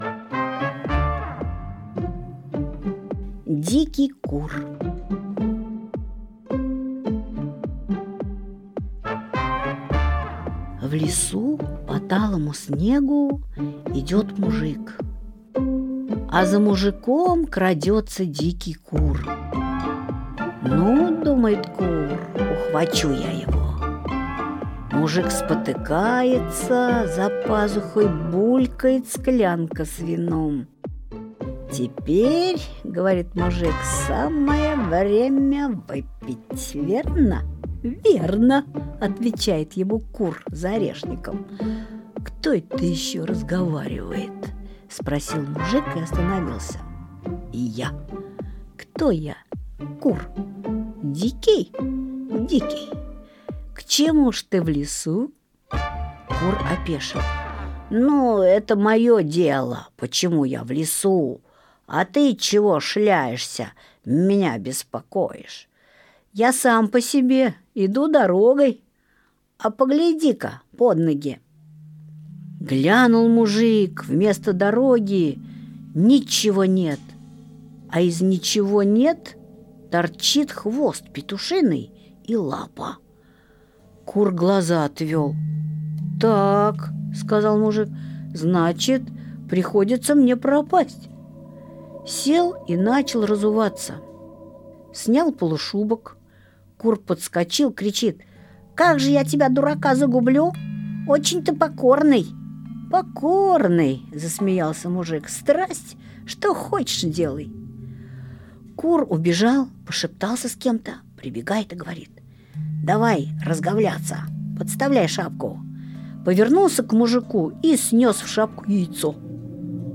Дикий кур – Толстой А.Н. (аудиоверсия)